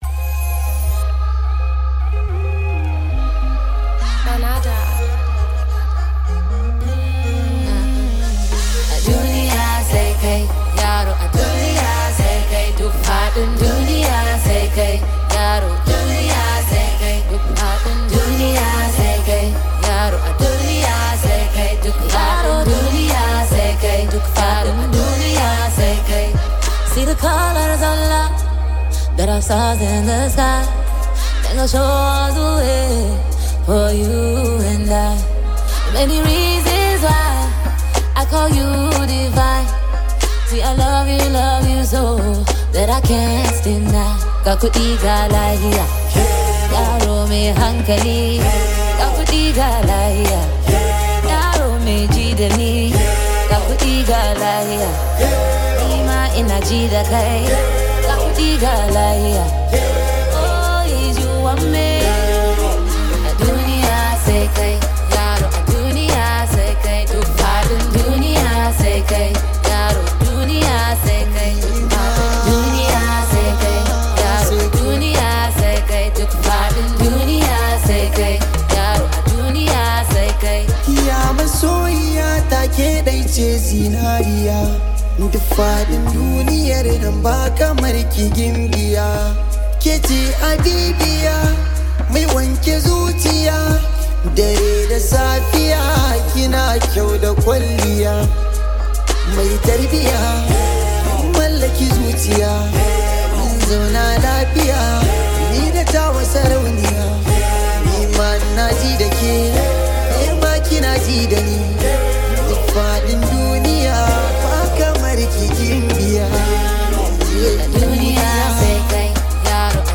Arewa-themed single